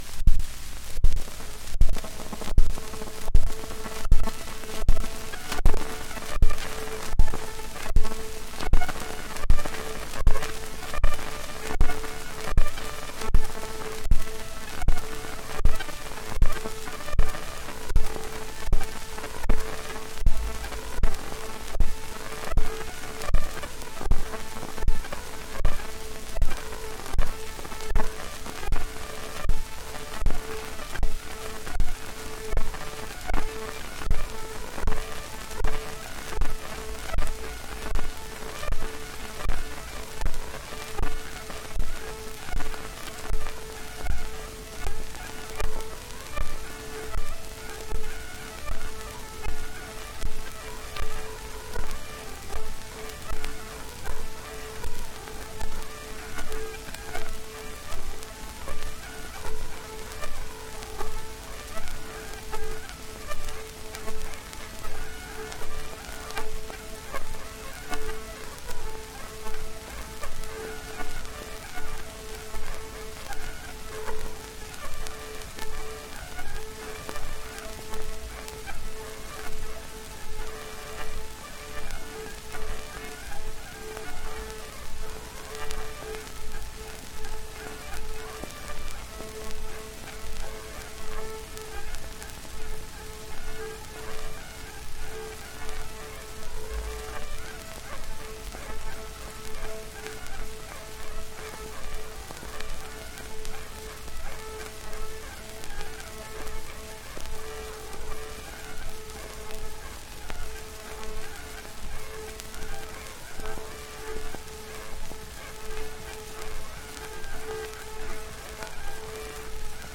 1 10 inch 78rpm shellac disc
S1 Highland Schottische S2 Sword Dance